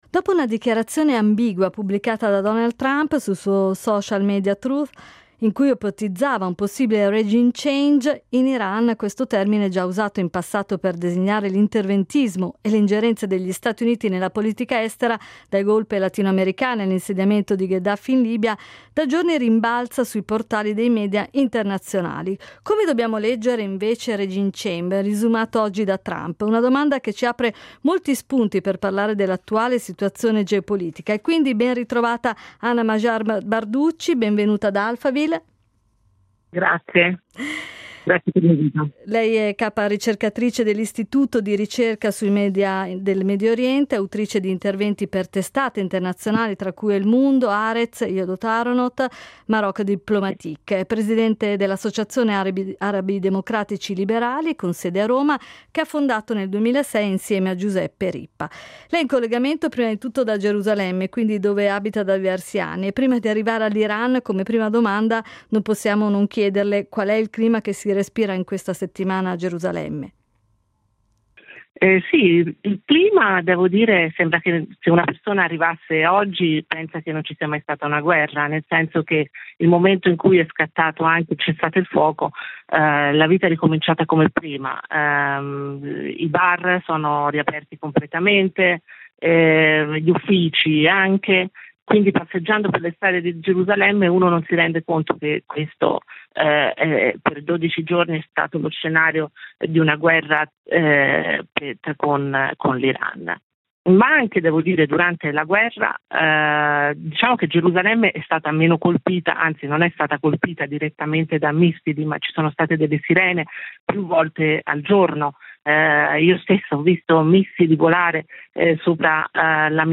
in collegamento con noi da Gerusalemme.